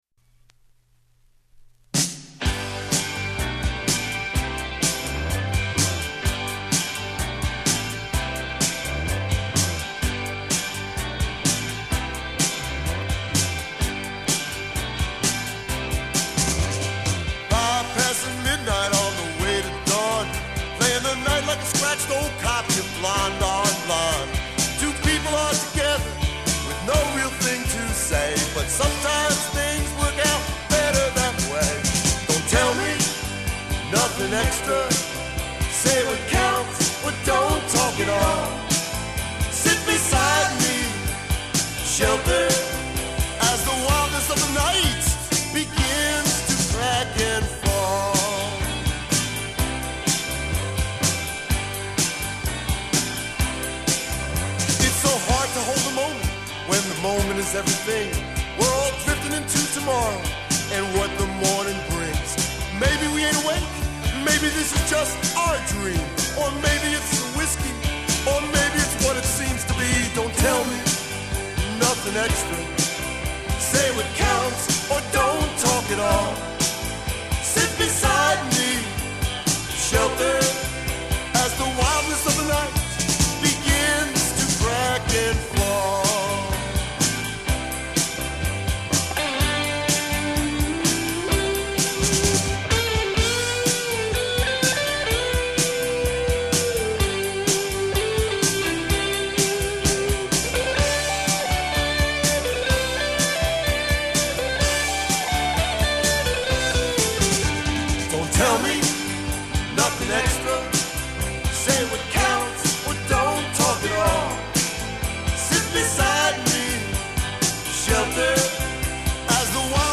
Recorded in the fall of 1980 on Long Island